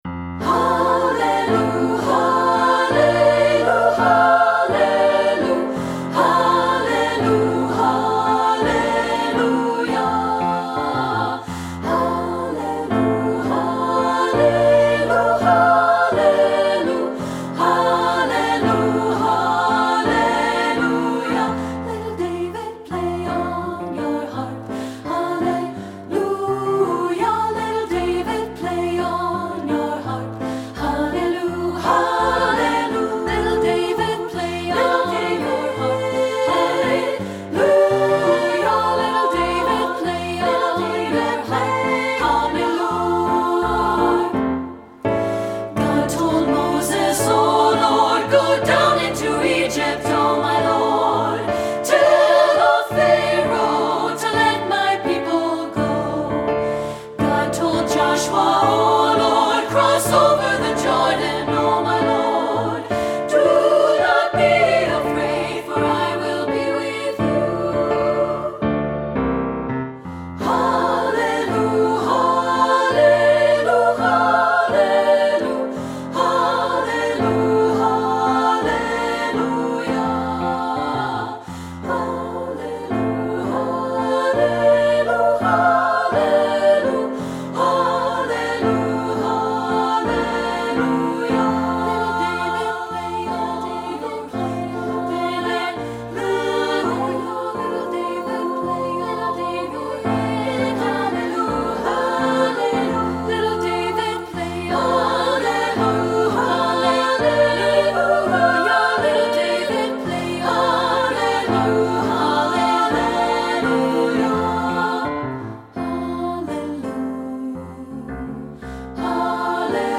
Composer: African-American Spiritual
Voicing: SSA